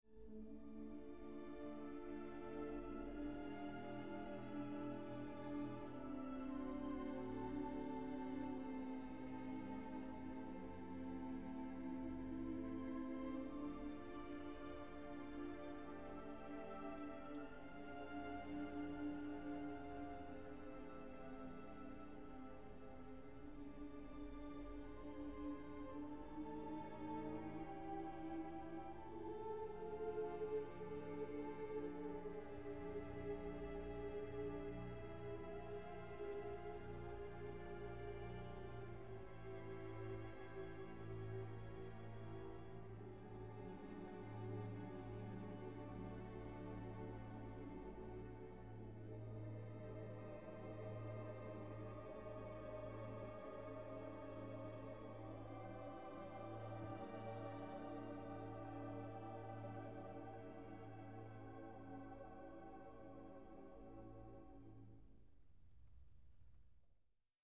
His last mountain-themed work was The Song of the High Hills (1911) for mixed chorus and large orchestra.
The chorus in this work sings no words; pure vocalization is employed as a means of poetic evocation.